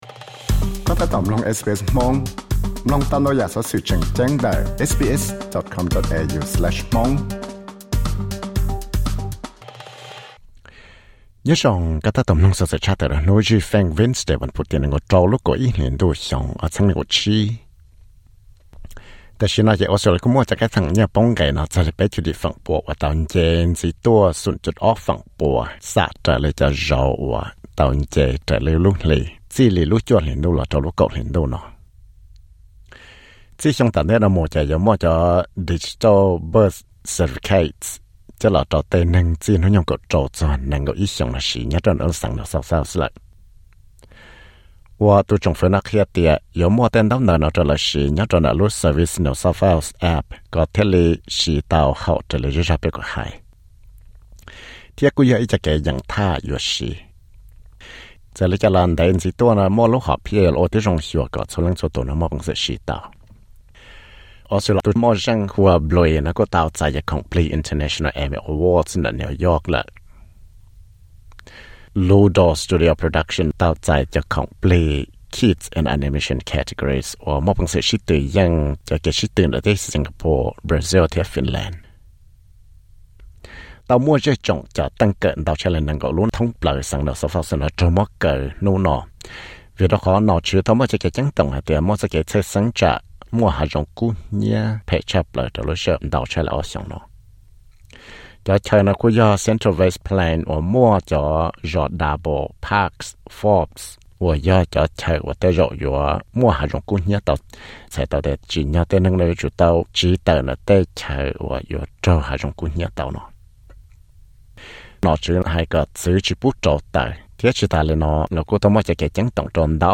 Wednesday newsflash: Australia tsab cai tswj cov genetic testing